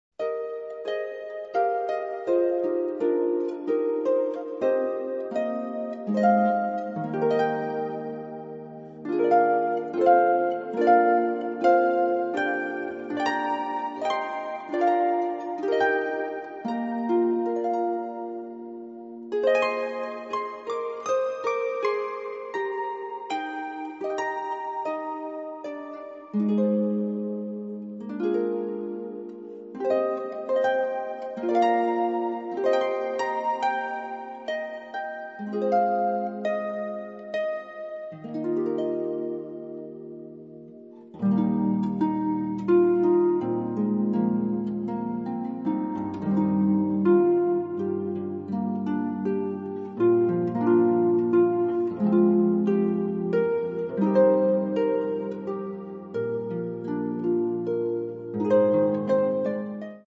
Genre: Hymns, Praise and Gospel